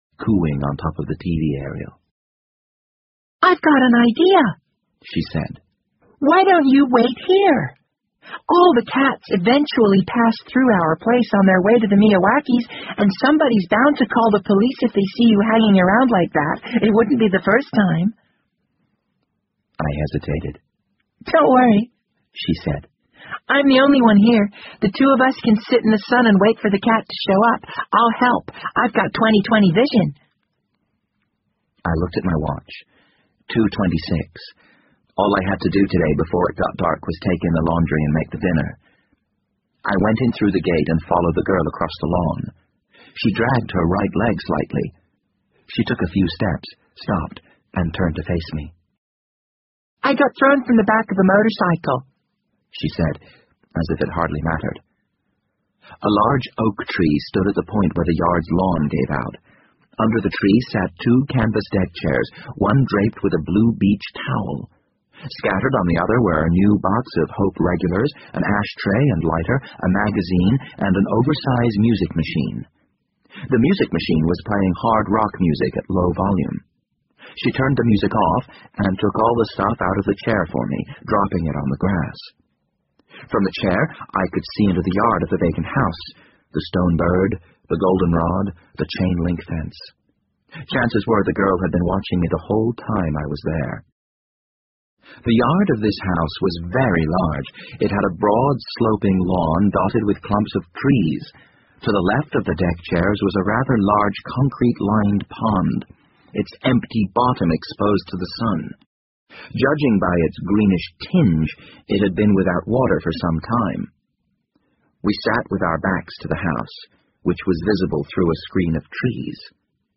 BBC英文广播剧在线听 The Wind Up Bird 7 听力文件下载—在线英语听力室